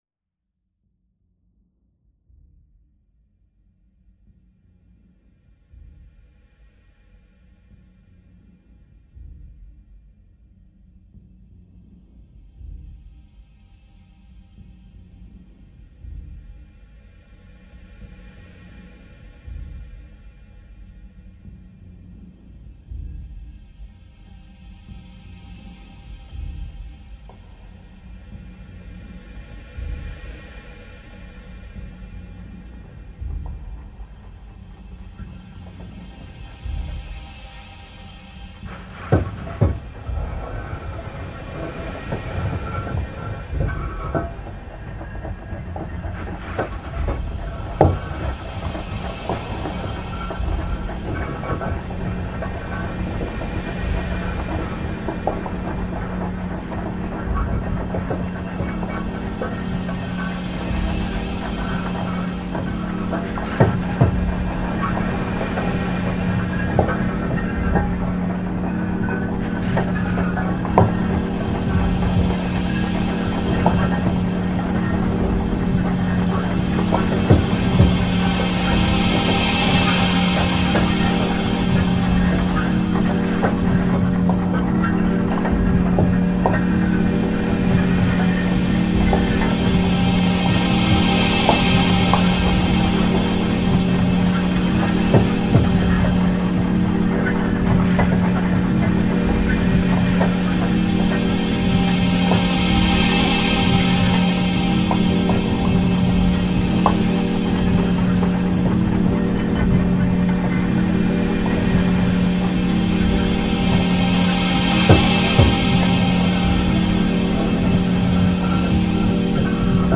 File under: Experimental